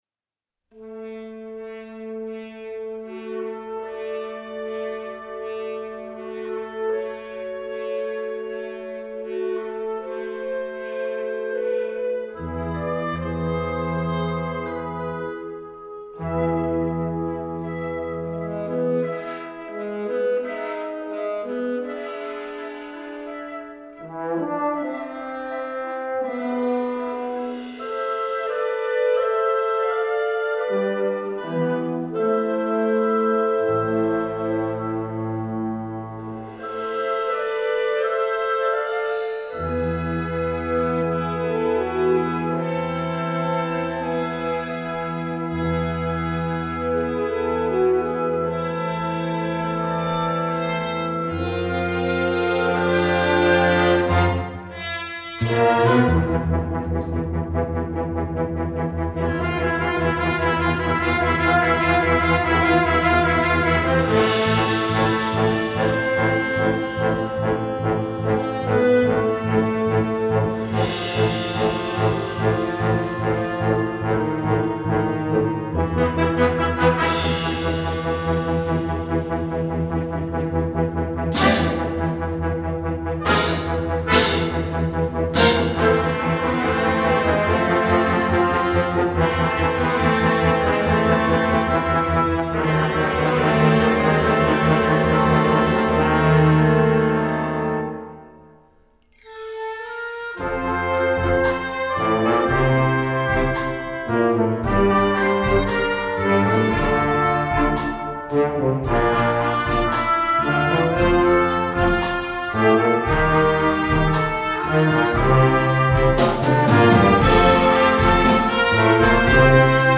Gattung: Jugendwerk - Filmmusik
4:21 Minuten Besetzung: Blasorchester PDF